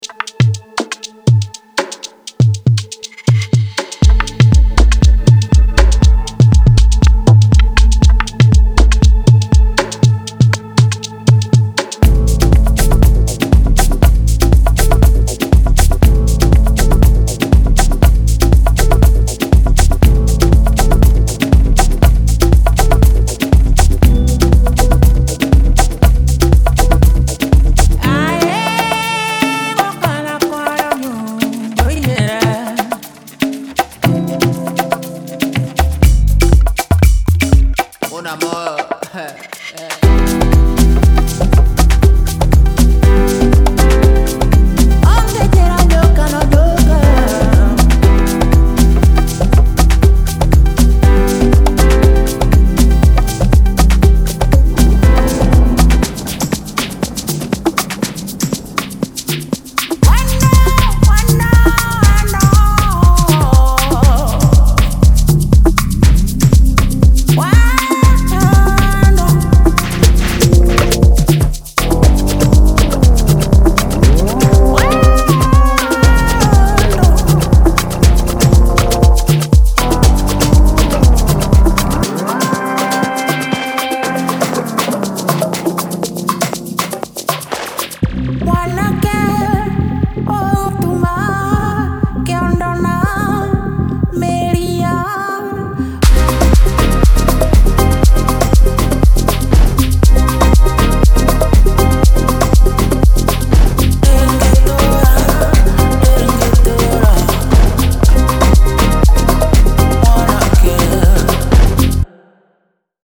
Genre:Afro House
パックには、トラックに不可欠なグルーヴとボディを与える15のディープで脈打つベースラインが収録されています。
表情豊かでメロディックな43のシンセは、トラックに催眠的で没入感のある雰囲気を作り出すのに理想的です。
デモサウンドはコチラ↓